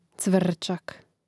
cvŕčak cvrčak